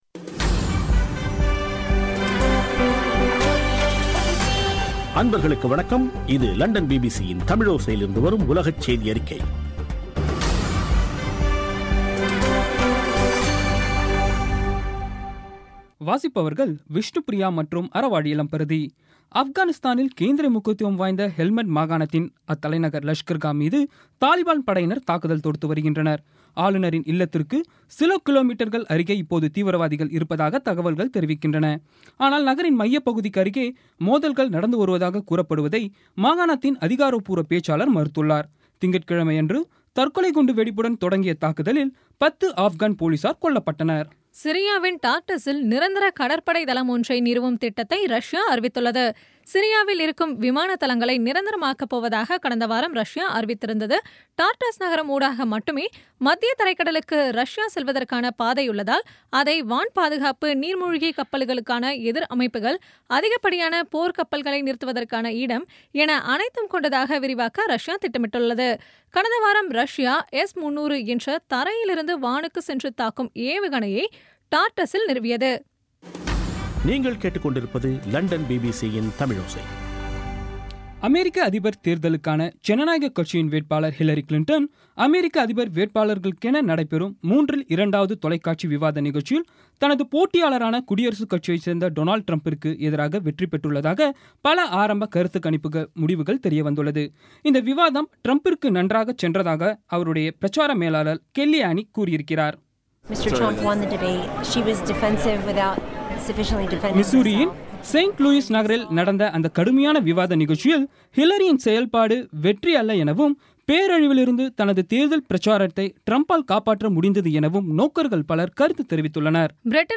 இன்றைய (அக்டோபர் 10ம் தேதி ) பிபிசி தமிழோசை செய்தியறிக்கை